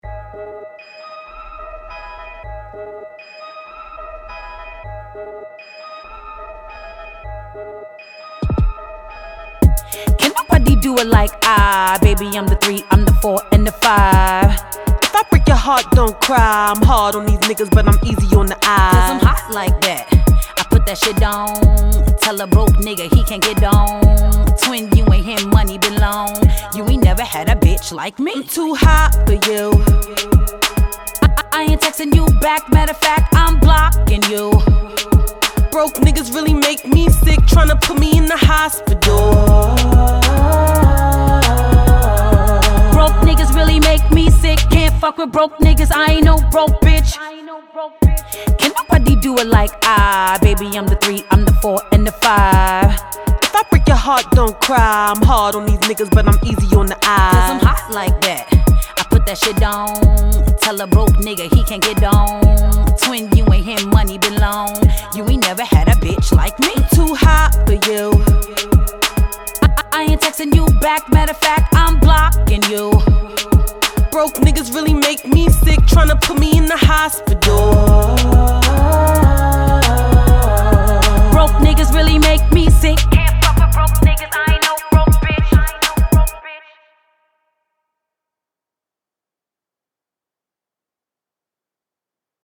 Trap, Hip Hop
G# Minor